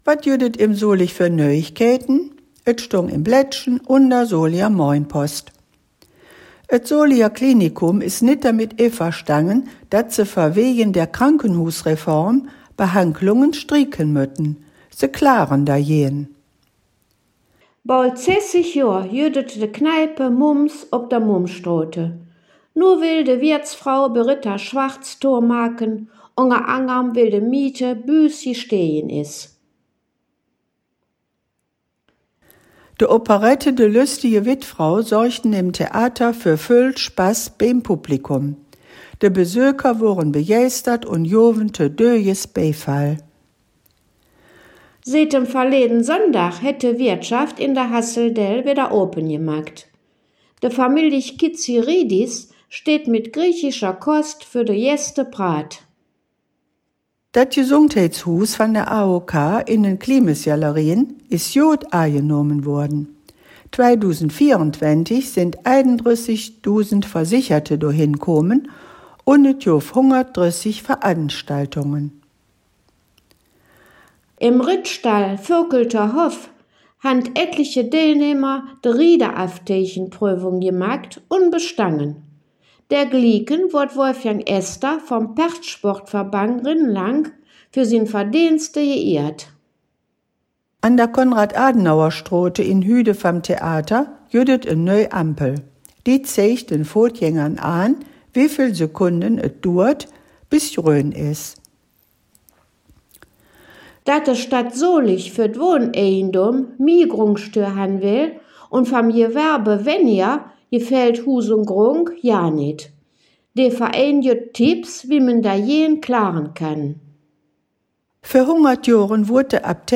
In dieser Folge "Dös Weeke em Solig" blicken de Hangkgeschmedden in Solinger Platt auf die Nachrichten vom 25. bis zum 31. Januar 2025 zurück.